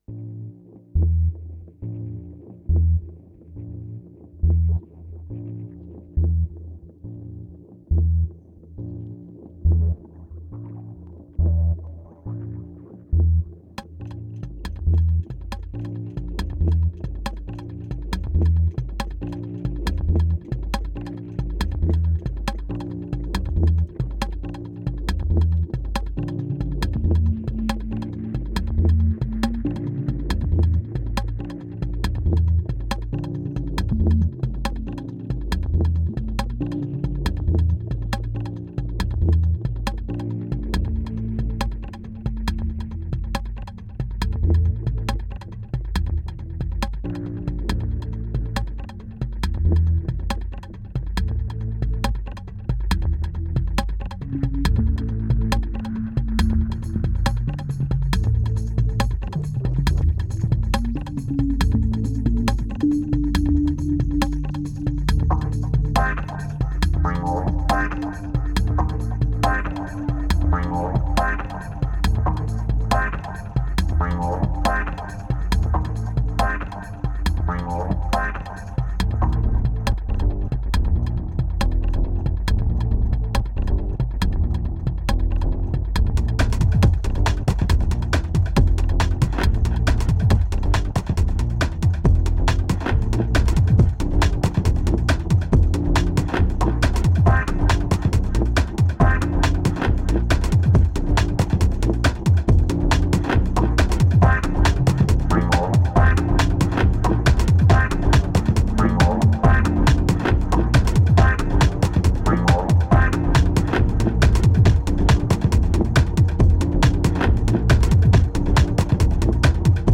a straightforward guitars album
2281📈 - 11%🤔 - 138BPM🔊 - 2011-09-14📅 - -353🌟